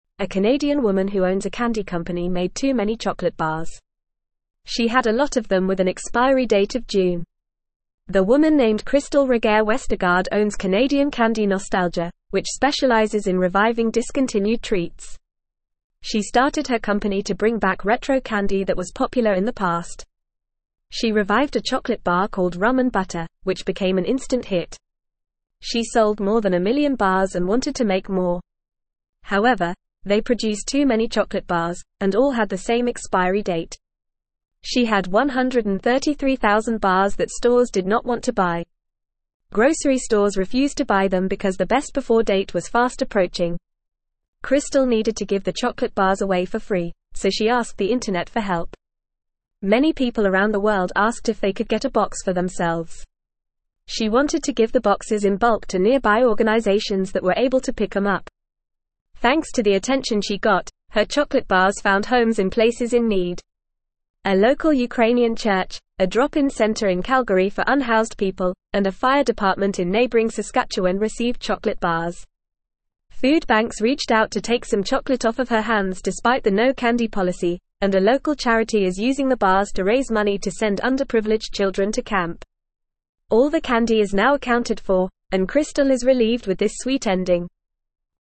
Fast
English-Newsroom-Beginner-FAST-Reading-Candy-Company-Gives-Excess-Chocolate-to-Charity.mp3